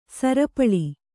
♪ sarapaḷi